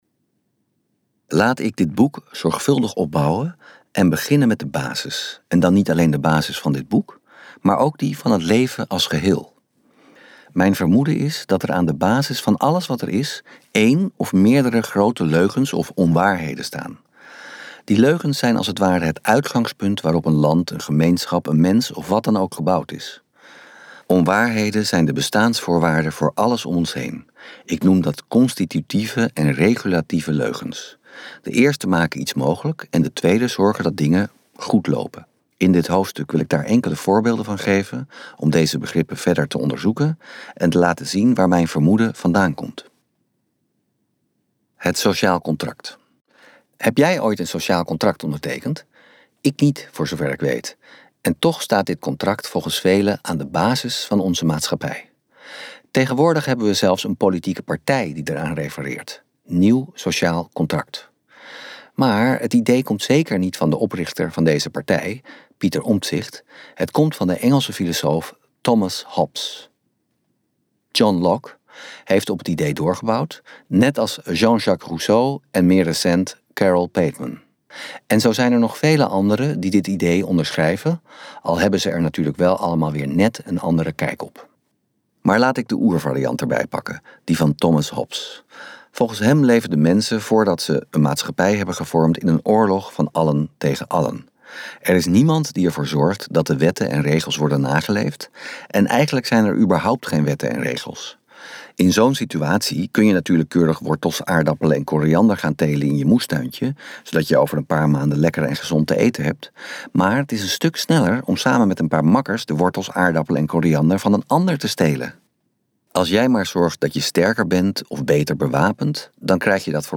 Uitgeverij Ten Have | Bluf jezelf door het leven luisterboek